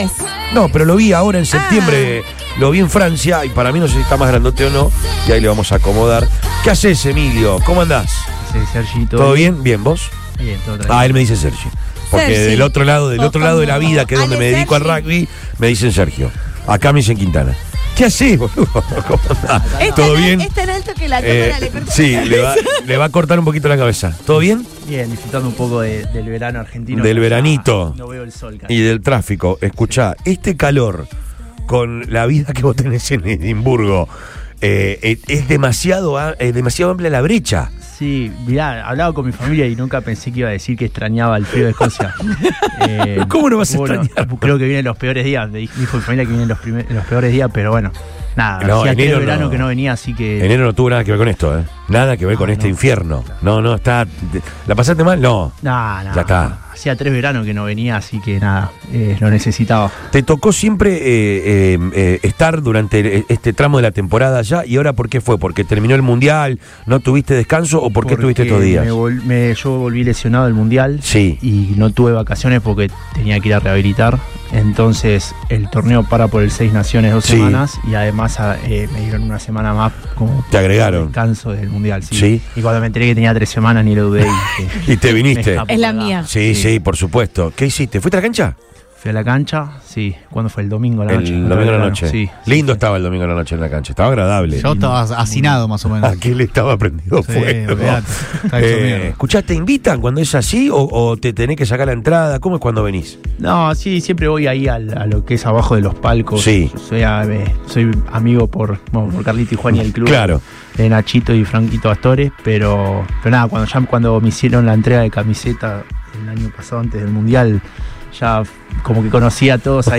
El jugador de los Pumas Emiliano Boffelli visitó los estudios de Radio Boing para charlar con Después de Todo. El nacido rugbísticamente en Duendes repasó sus sensaciones luego de lo que fue el mundial de rugby 2023, su pasión por la ciudad y lo que viene a futuro.